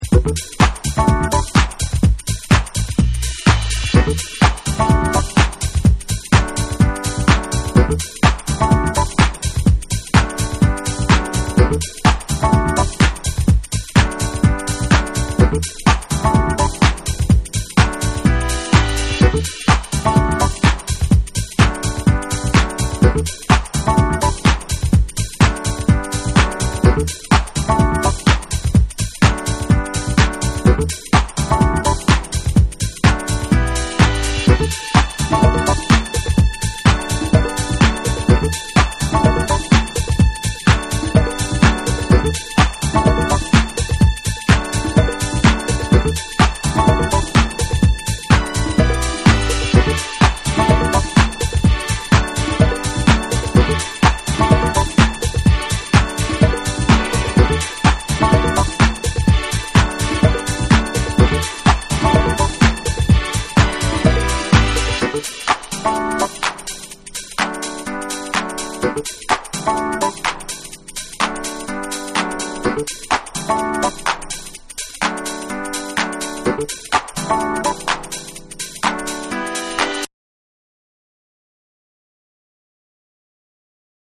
気持ち良くハネの効いたトラックにUS産のハウスにも通ずるピアノのフレーズやストリングス・ワーク
TECHNO & HOUSE